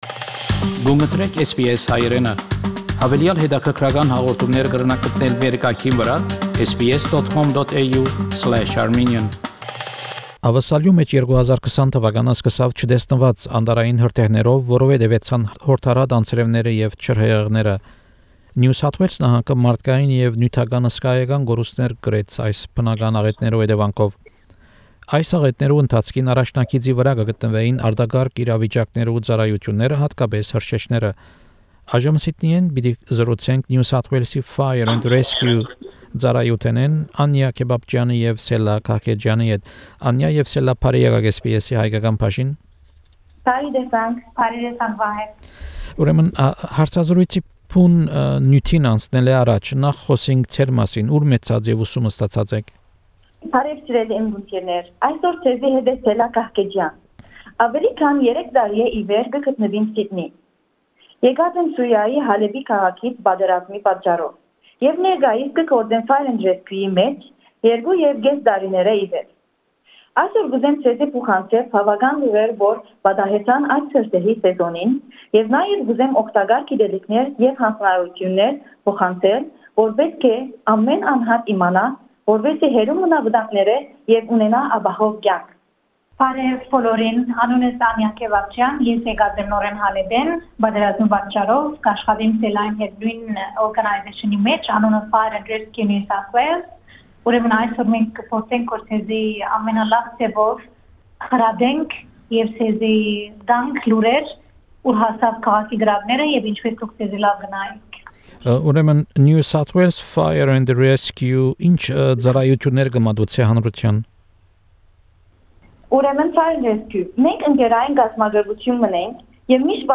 An interview (in Armenian)